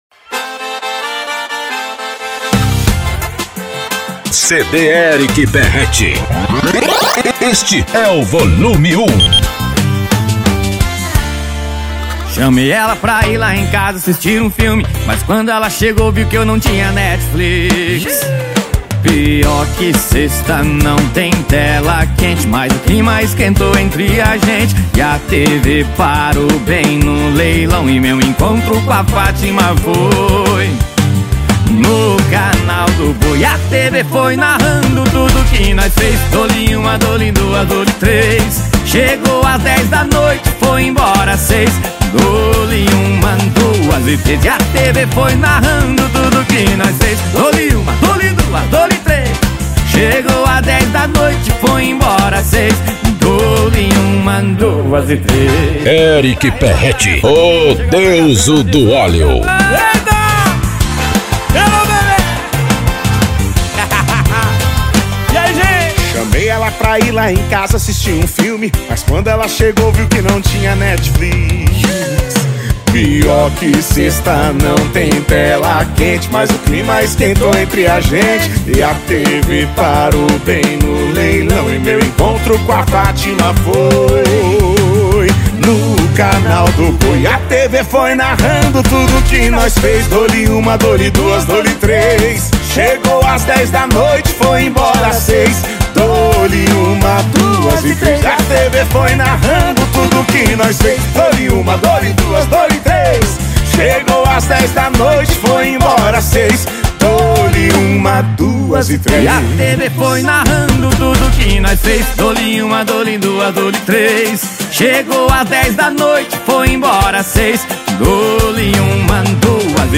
Sertanejo Universitário